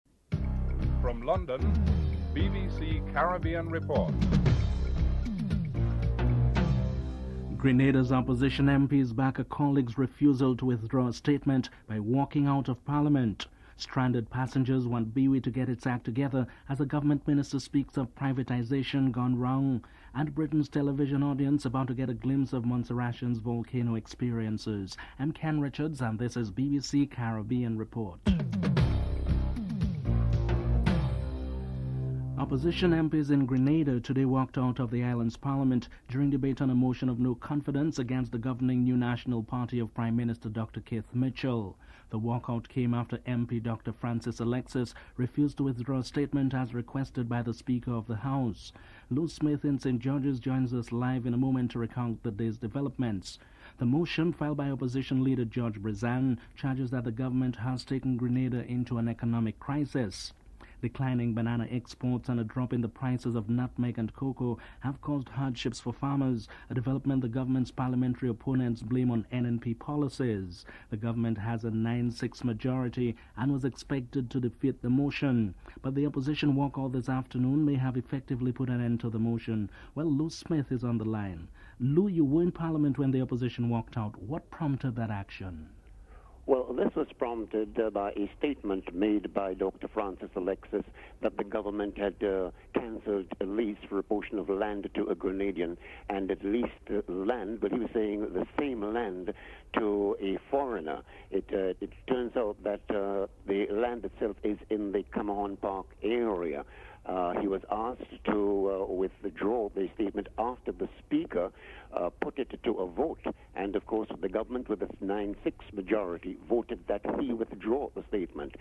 The British Broadcasting Corporation
1. Headlines (00:00-00:31)